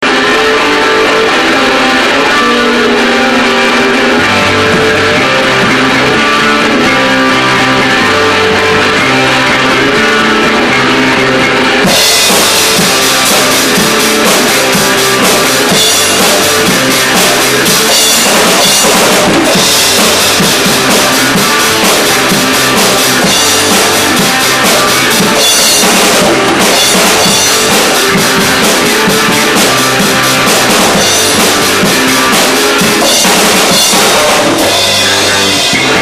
I don't know what kind of introduction would have been proper for our band section... we're a garage band from Palm Harbor. we play rock.
All the material available below was recorded using a Hi8 sony camcorder. the a double ended headphone cable was run to my mic input and sound recorder was used to record from the tape.
Improvised Jam